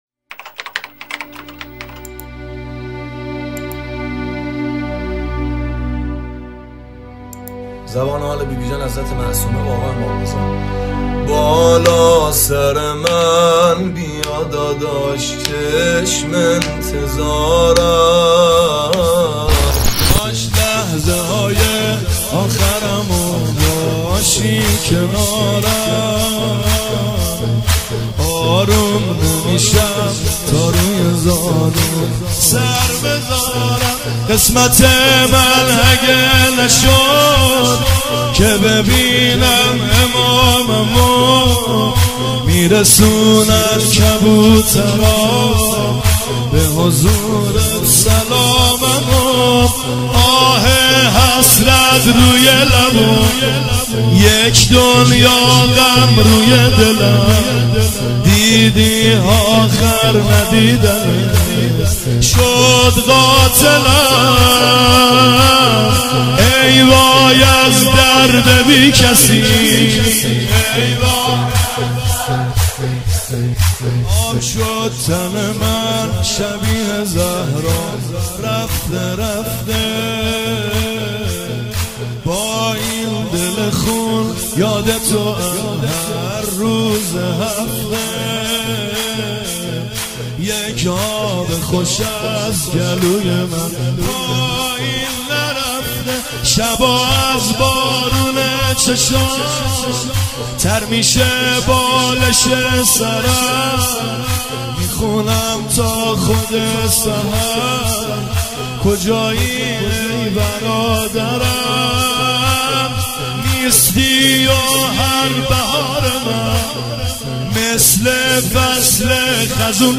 شهادت حضرت معصومه (س) | هیئت جانثاران امام زمان (عج)